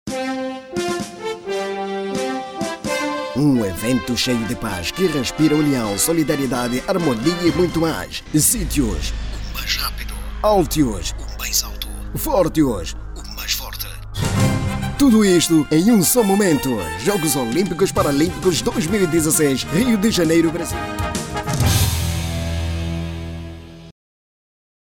Masculino
Voz Padrão - Grave 00:24